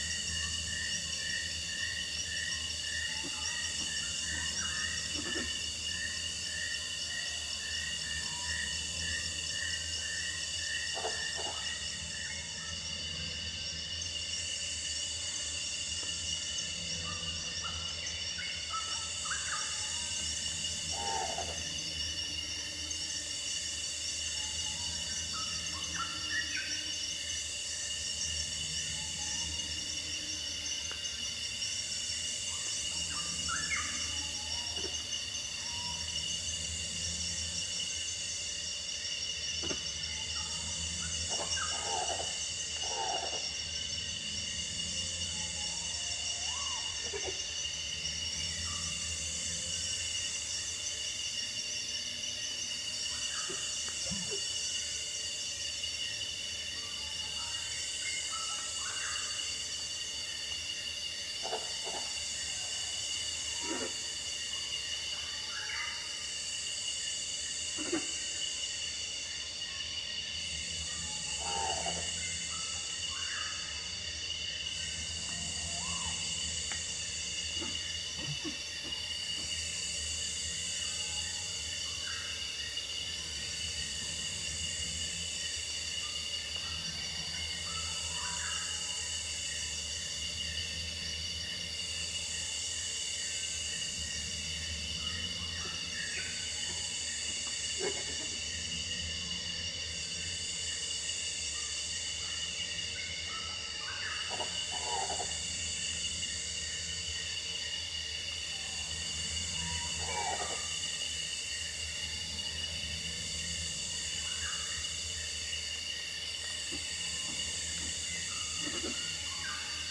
jungenature.ogg